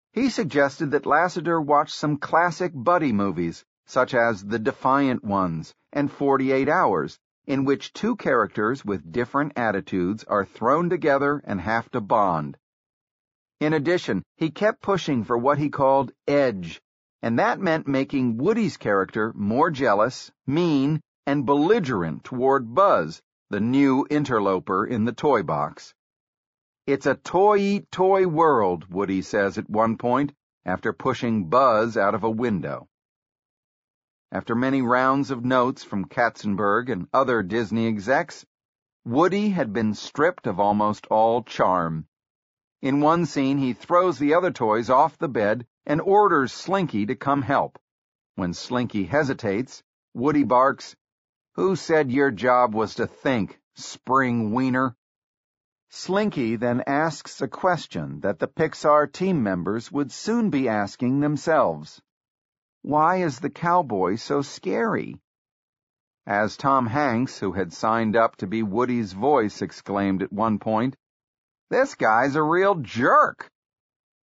本栏目纯正的英语发音，以及完整的传记内容，详细描述了乔布斯的一生，是学习英语的必备材料。